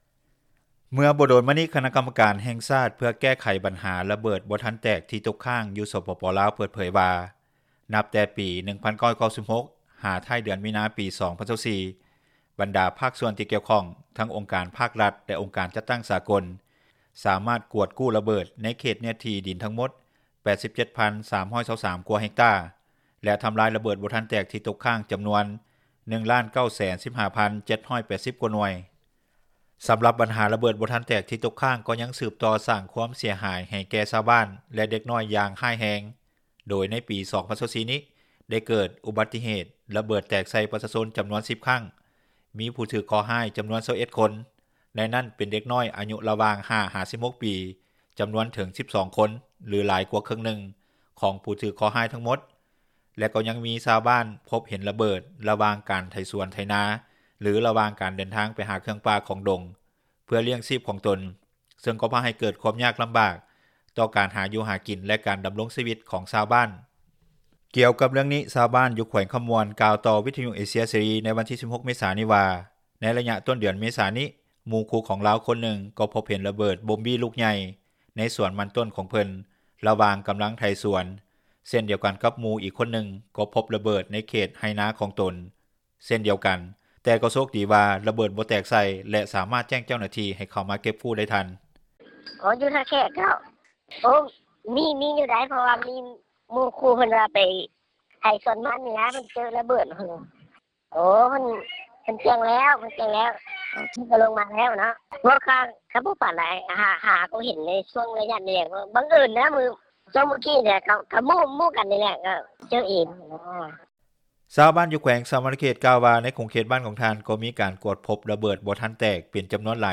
ຊາວບ້ານ ຢູ່ແຂວງຈໍາປາສັກ ກ່າວວ່າ ທ່ານກໍພົບເຫັນລູກລະເບີດບໍ່ທັນແຕກ ຢູ່ລະຫວ່າງທາງໄປສວນຂອງຍາດຕິພີ່ນ້ອງລາວເປັນປະຈຳ ຊຶ່ງກໍ່ໄດ້ສ້າງຄວາມຢ້ານກົວ ຕໍ່ຊາວບ້ານ ໃນການເດີນທາງໄປຮົ້ວສວນຂອງເຂົາເຈົ້າ.
ແຕ່ວຽກງານເກັບກູ້ລະເບີດຢູ່ລາວ ກໍຍັງມີຄວາມຊັກຊ້າ. ດັ່ງທີ່ ທ່ານ ສະເຫຼີມໄຊ ກົມມະສິດ, ຮອງນາຍົກລັດຖະມົນຕີ ແລະ ລັດຖະມົນຕີ ກະຊວງການຕ່າງປະເທດ ທັງເປັນປະທານ ຄະນະກຳມະການຄຸ້ມຄອງແຫ່ງຊາດ ເພື່ອແກ້ໄຂບັນຫາ ລະເບີດບໍ່ທັນແຕກ ທີ່ຕົກຄ້າງຢູ່ ສປປ ລາວ ເຄີຍກ່າວຍອມຮັບ ກ່ຽວກັບຂໍ້ຫຍຸ້ງຍາກ ຂອງວຽກງານເກັບກູ້ລະເບີດຢູ່ລາວ ເມື່ອຫວ່າງບໍ່ດົນມານີ້ວ່າ.